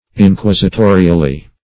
Inquisitorially \In*quis`i*to"ri*al*ly\, adv. In an inquisitorial manner.